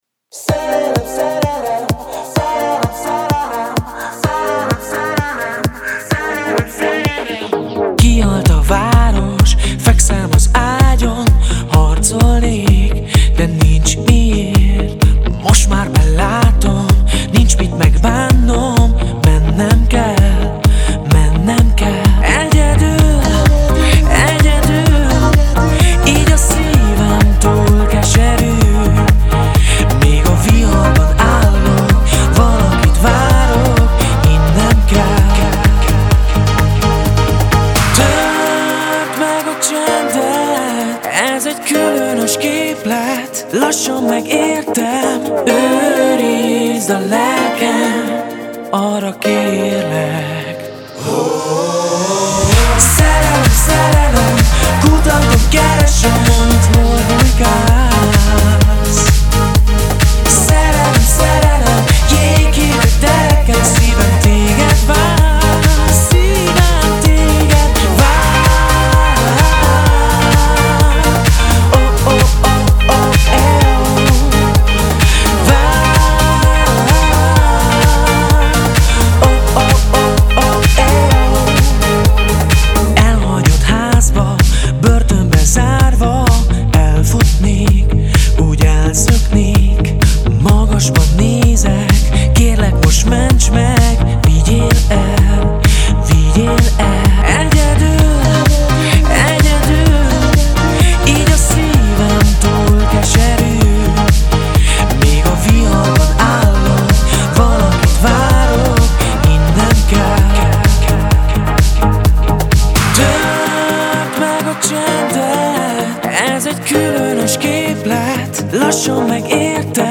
1. Radio version
Stílus: Pop